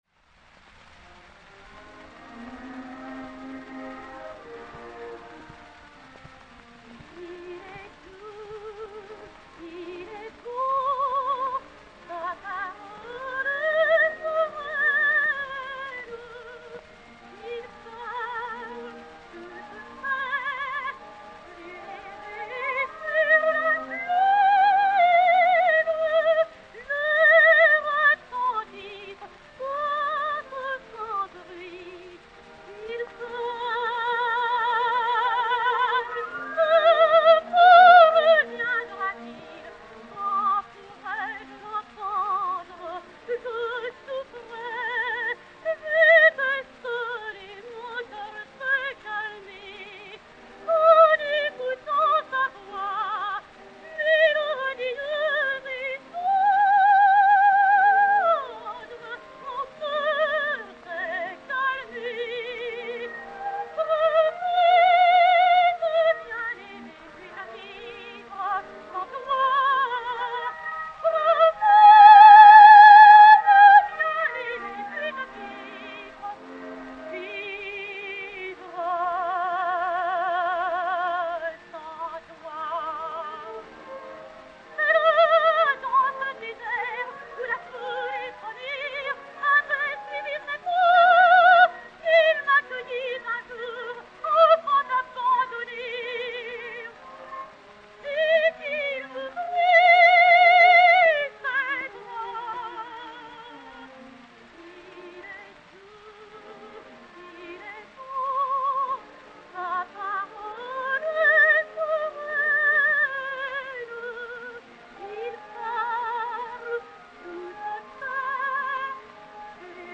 Marthe Chenal (Salomé) et Orchestre dir. François Rühlmann